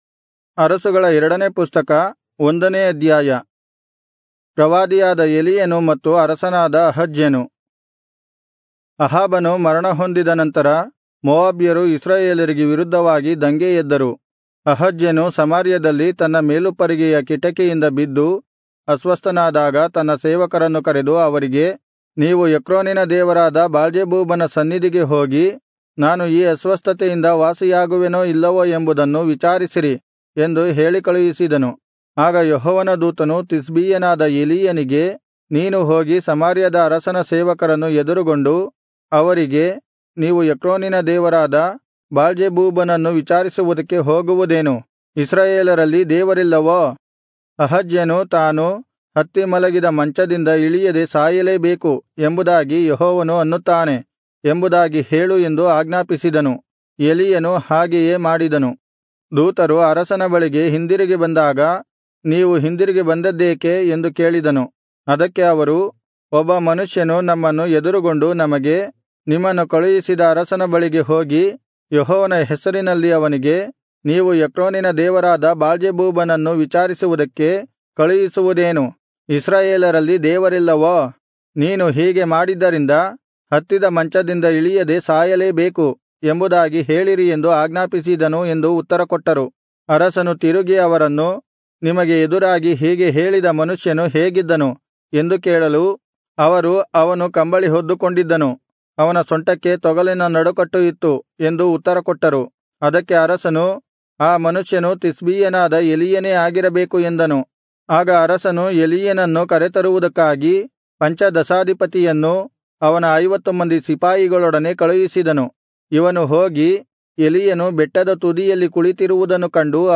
Kannada Audio Bible - 2-Kings 6 in Irvkn bible version